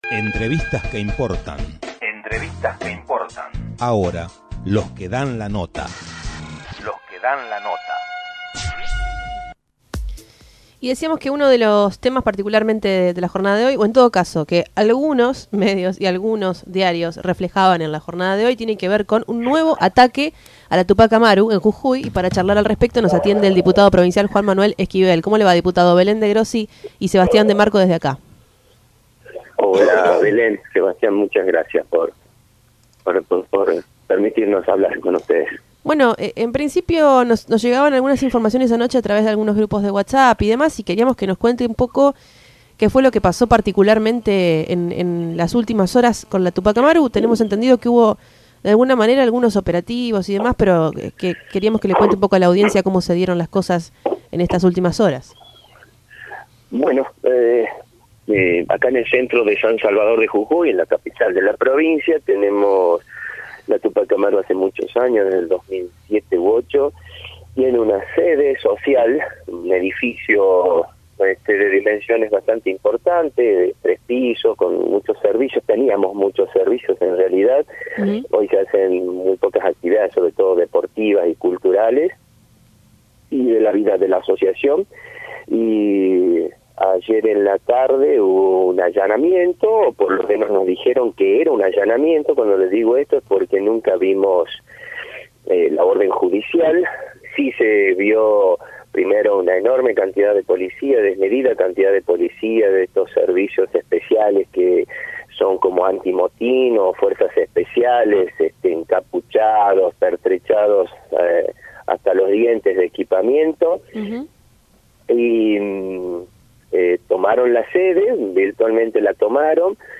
Así se refería el Diputado Provincial Juan Manuel Esquivel, quien relato como se desarrolló el allanamiento a la sede de la Tupac Amaru en San Salvador de Jujuy.
Diputado provincial Juan Manuel Esquivel, San Salvador de Jujuy